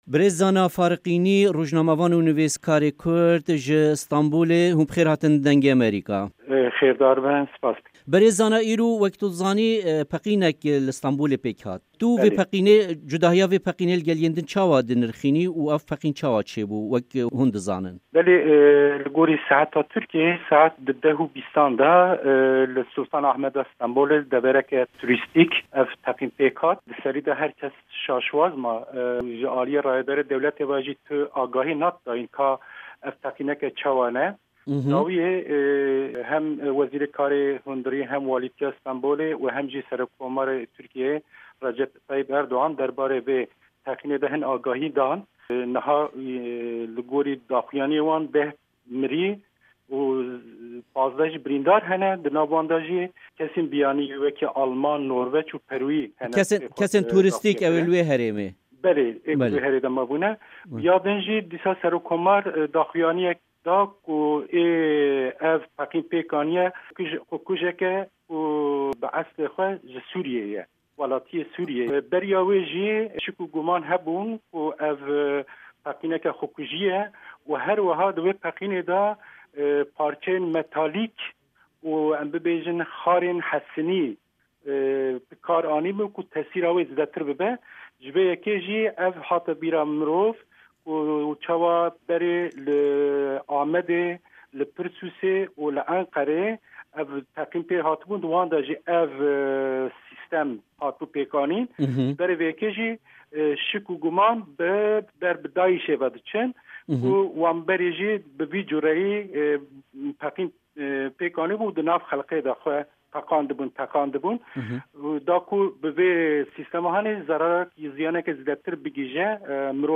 Temamiya hevpeyvînê di Fayla Deng de ye.